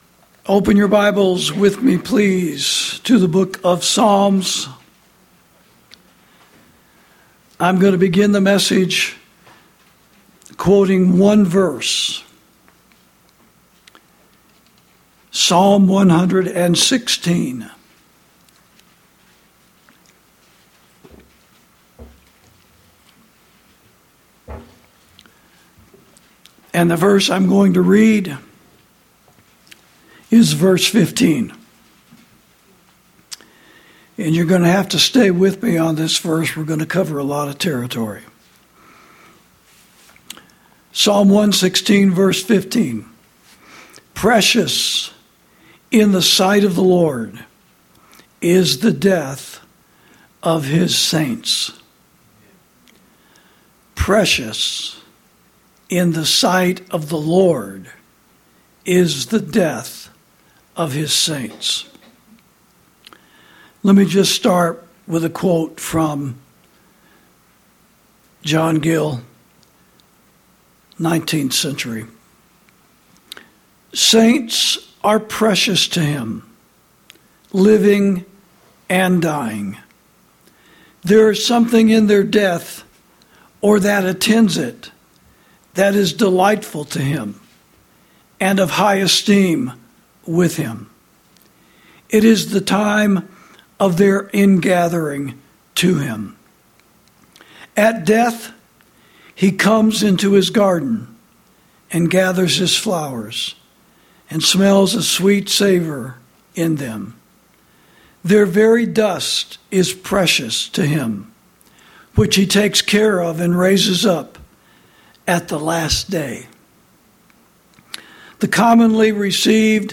Sermons > Precious In The Sight Of The Lord Is The Death Of His Saints: We Are The Lord's